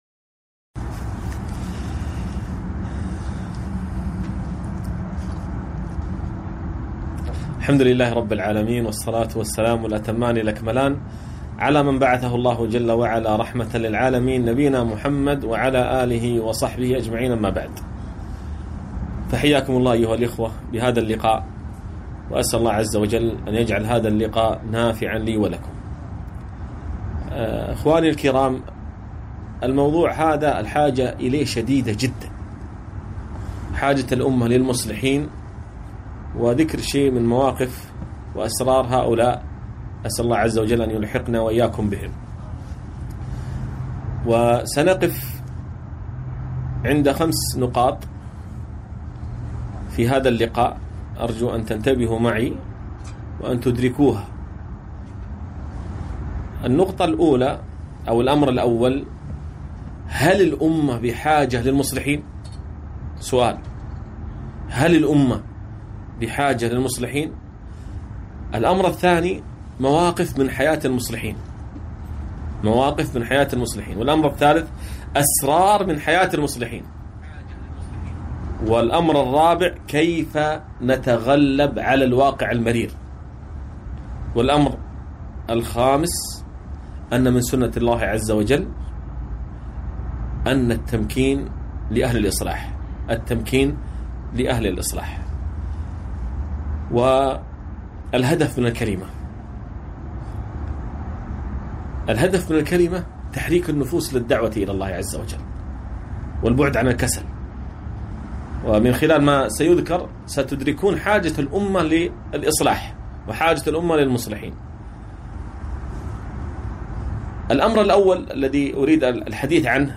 محاضرة - حاجة الأمة للمصلحين